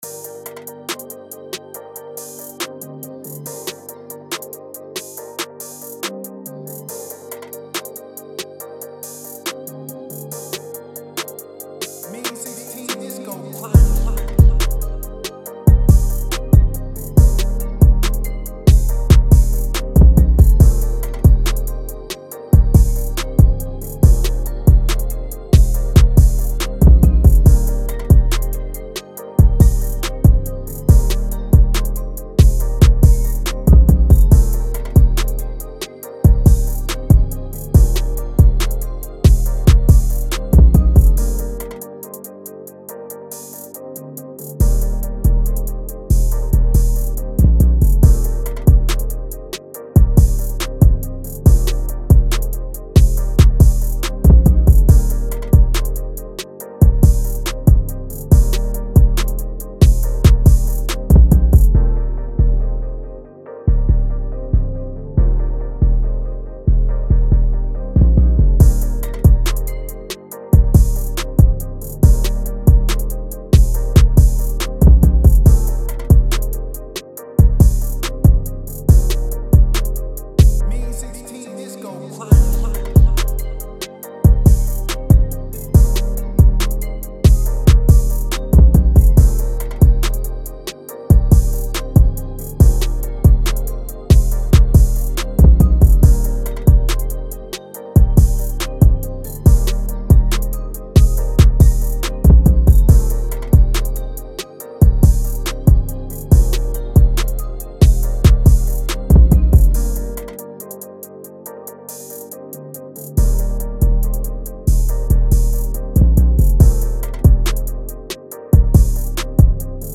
G-Min 140-BPM